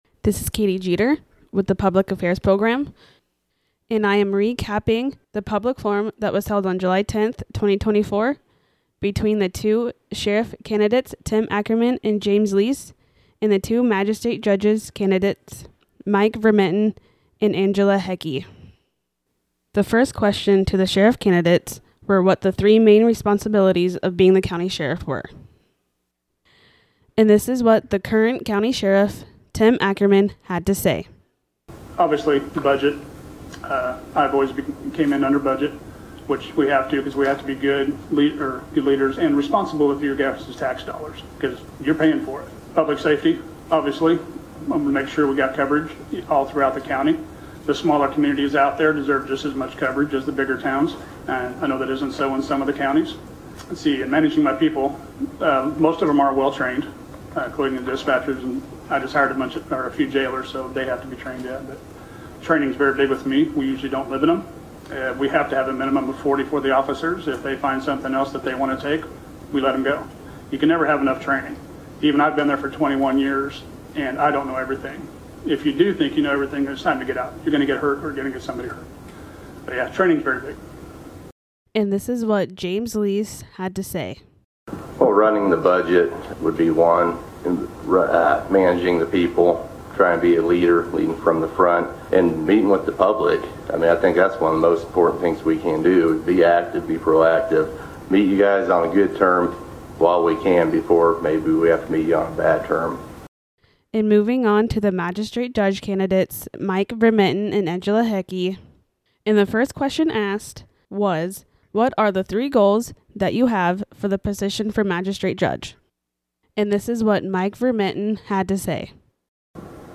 KNDY NEWS PODCAST